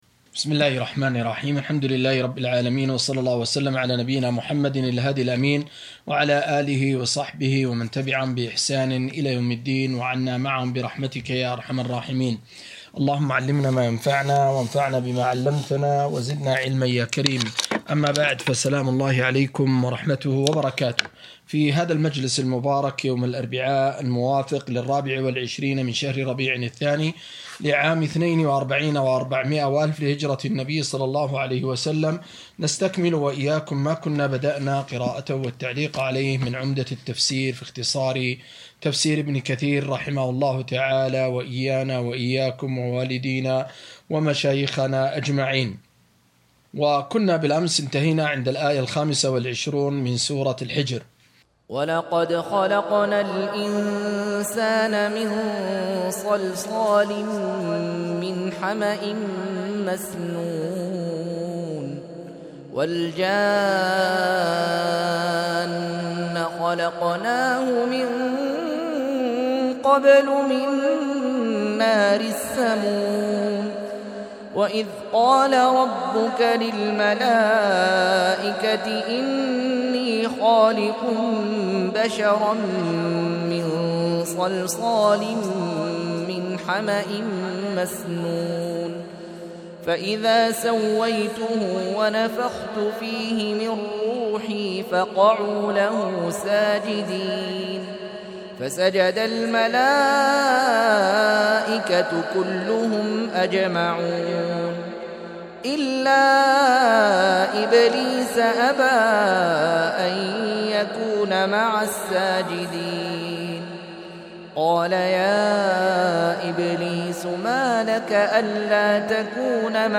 255- عمدة التفسير عن الحافظ ابن كثير رحمه الله للعلامة أحمد شاكر رحمه الله – قراءة وتعليق –